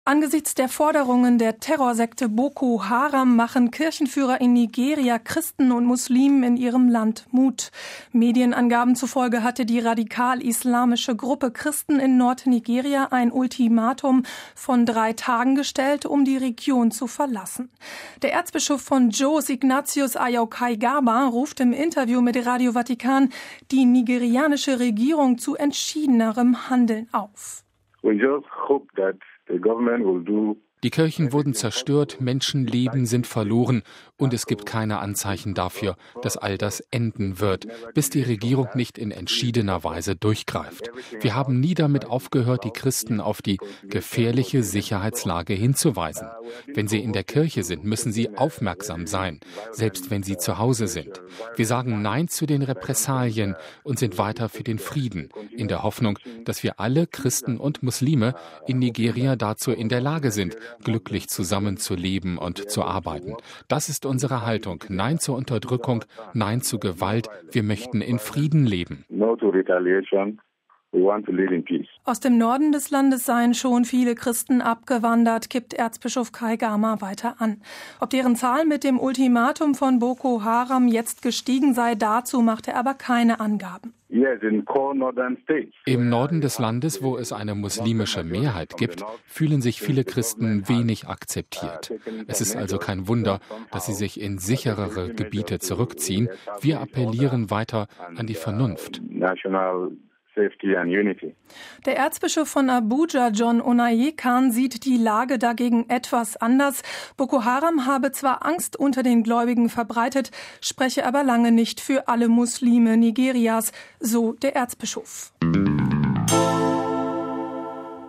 Der Erzbischof von Jos, Ignatius Ayau Kaigama, ruft im Interview mit Radio Vatikan die nigerianische Regierung zu entschiedenerem Handeln auf: